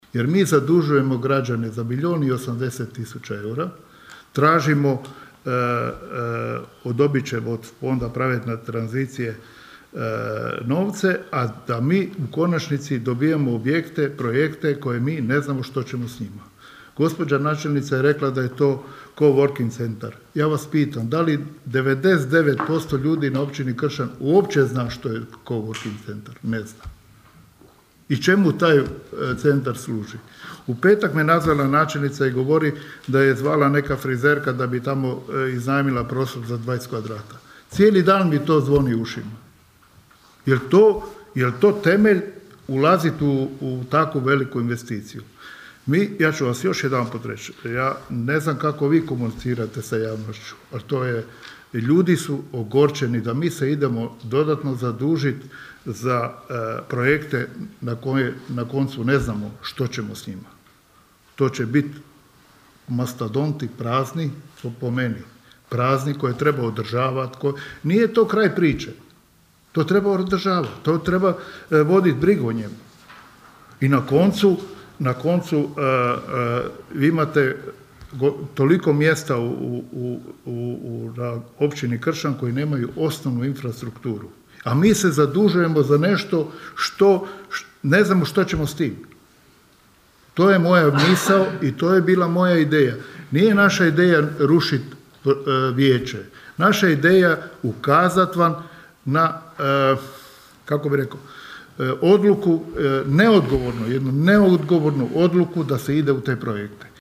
Sjednica Općina Kršan
"Biste li vi osobno išli u takve investicije koje su rizične", pitao je nezavisni vijećnik, inače potpredsjednik Vijeća, koji je svoju podršku na početku mandata dao IDS-u, a sada se okrenuo oporbenim vijećnicima, Robert Stepčić: (